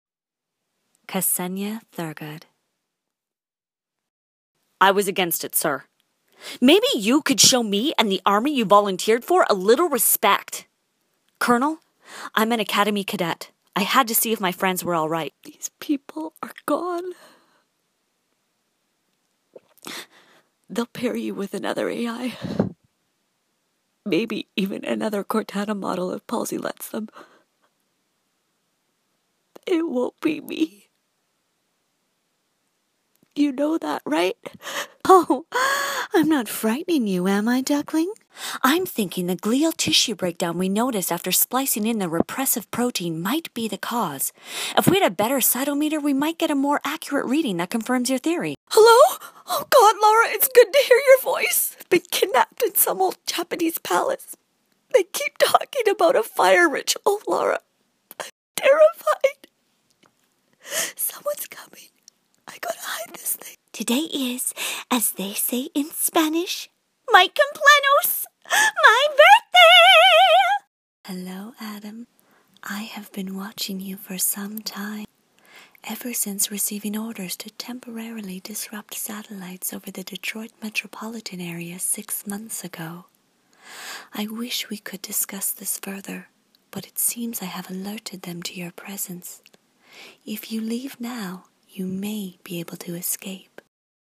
Jeux vidéo - ANG